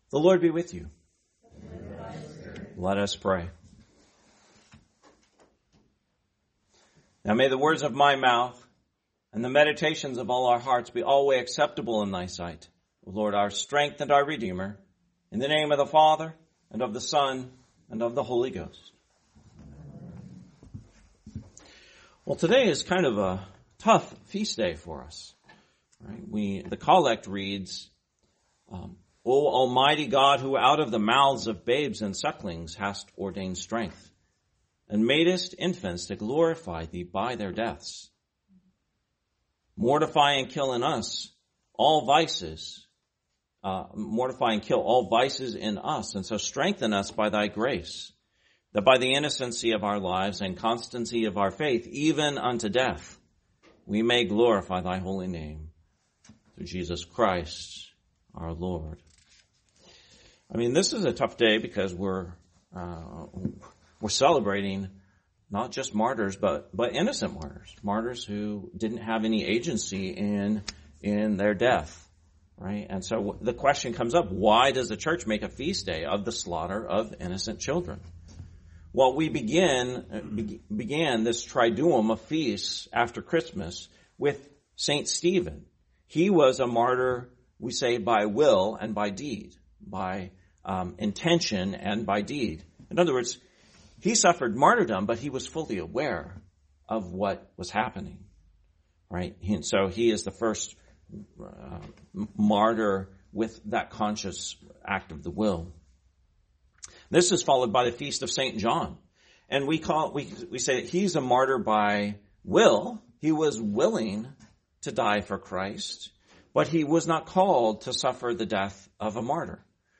Sermon, Feast of the Holy Innocents, 2025